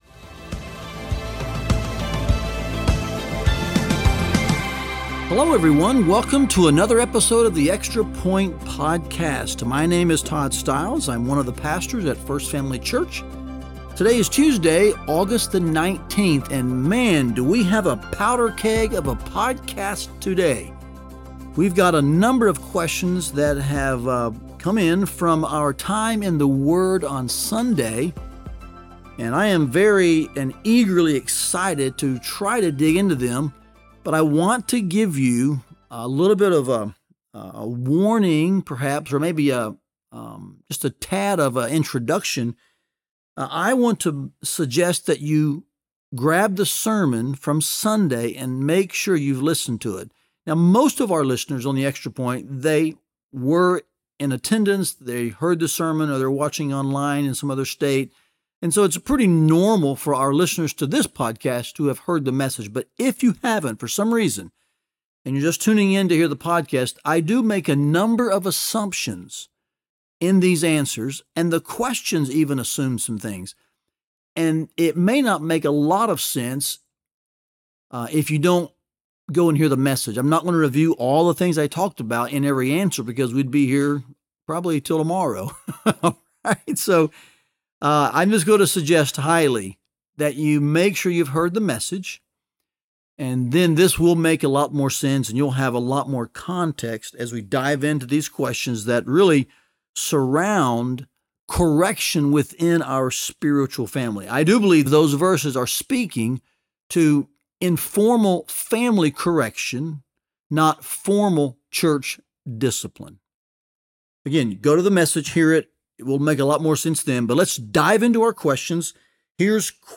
Q&A around correction in the spiritual family - Part 1